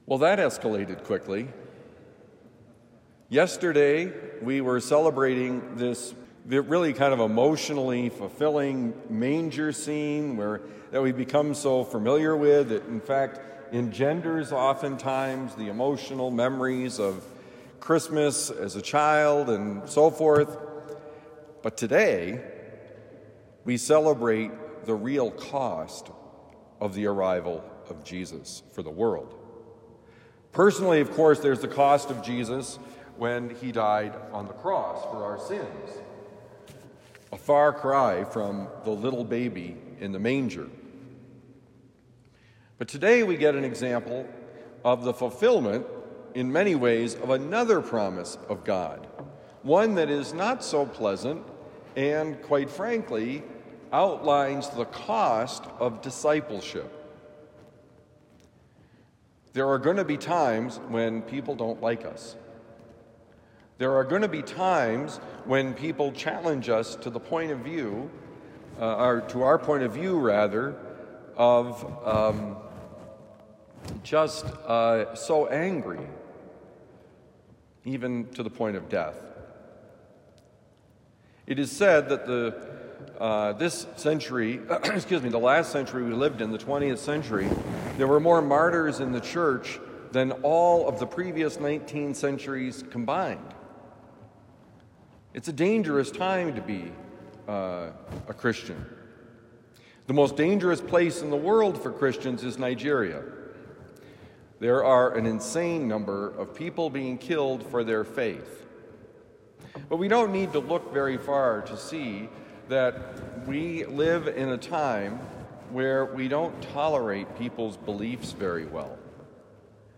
Well that escalated quickly: Homily for Thursday, December 26, 2024